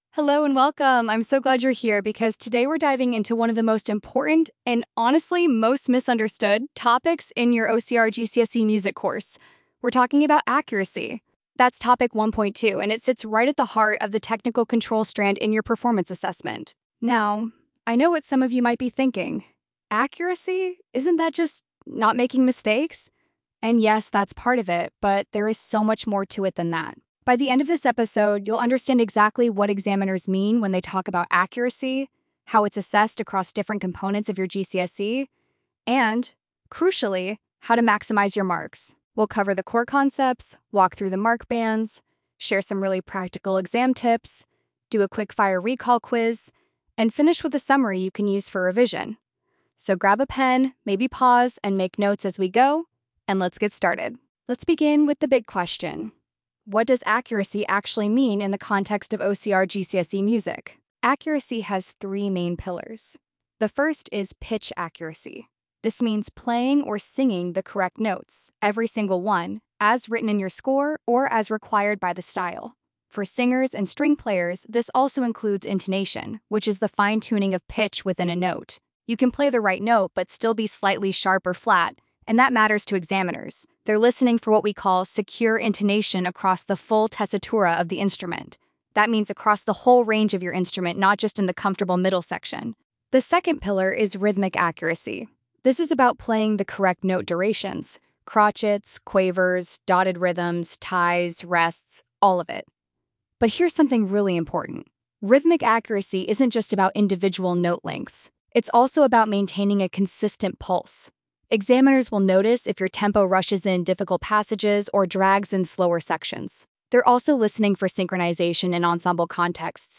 accuracy_podcast.mp3